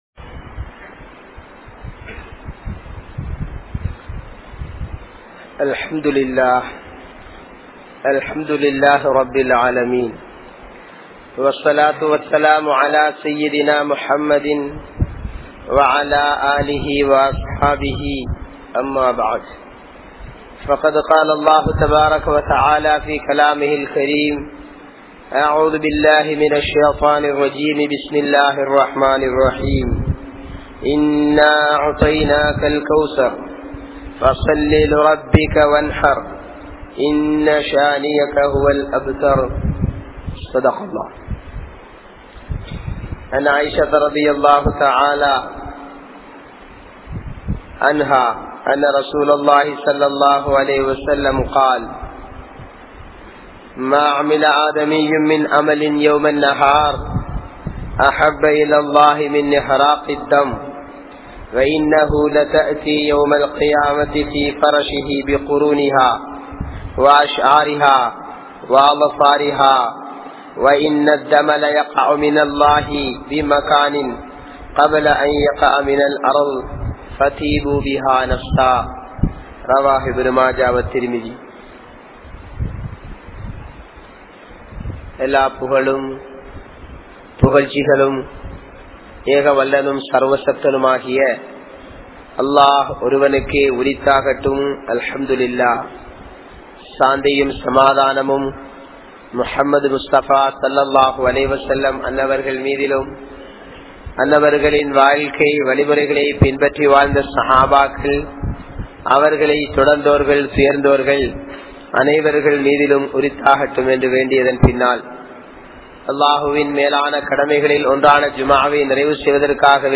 Ulhiyya(உழ்ஹிய்யா) | Audio Bayans | All Ceylon Muslim Youth Community | Addalaichenai